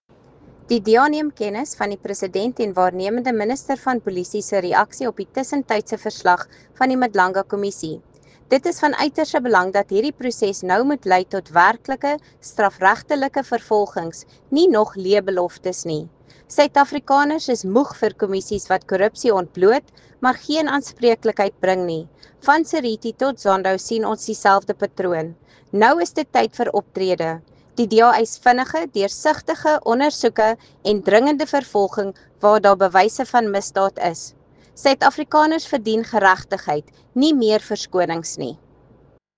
English soundbite by Lisa Schickerling MP